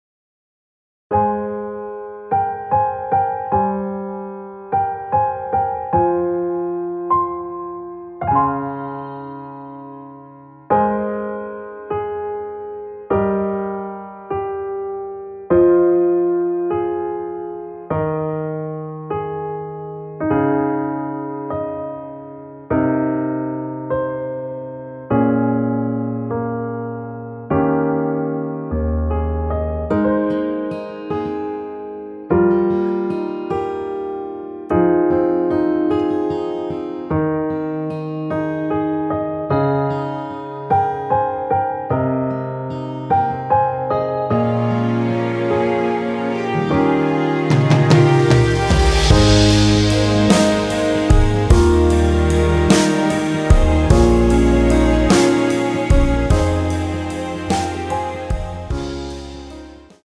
끝없는 밤을 걷자후에 2절의 나의 모든 날들을로 진행이 됩니다.
Ab
앞부분30초, 뒷부분30초씩 편집해서 올려 드리고 있습니다.